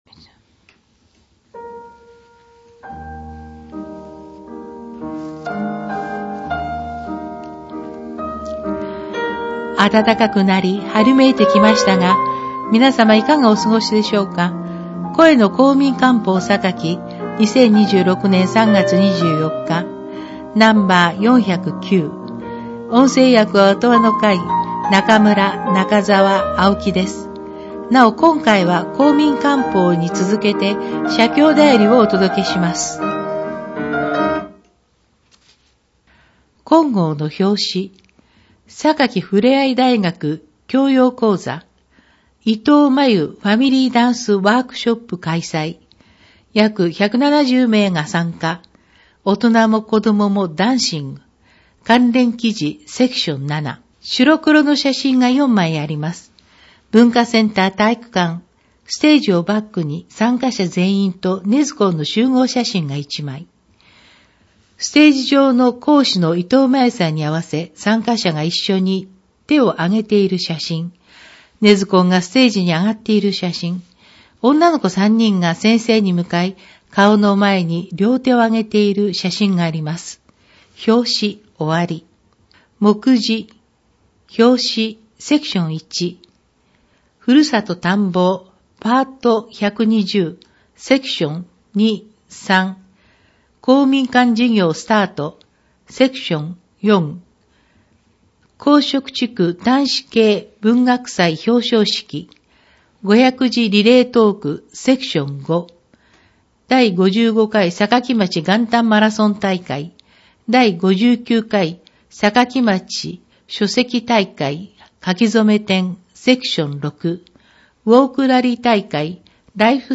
また、音訳ボランティアサークルおとわの会のみなさんによる広報の音訳版のダウンロードもご利用ください。
音訳版ダウンロード(制作：おとわの会）